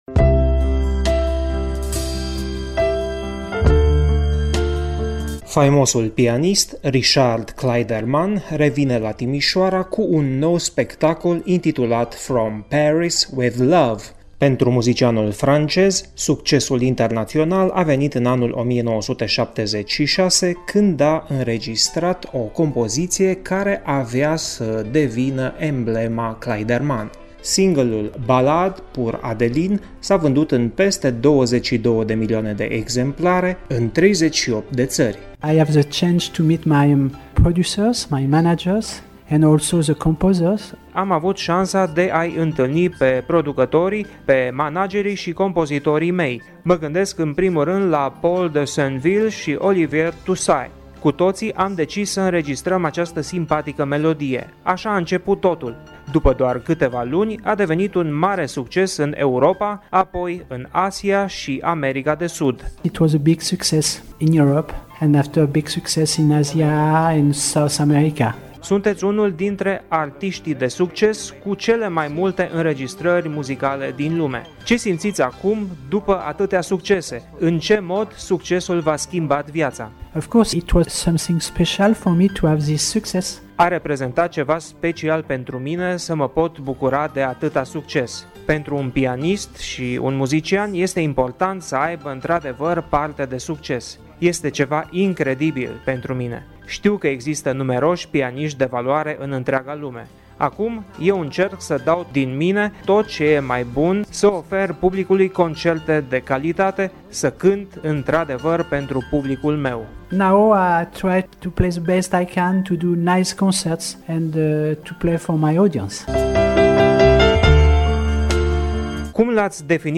Ascultați pe scurt povestea începutului carierei, dar și a succesului ulterior, chiar de la artist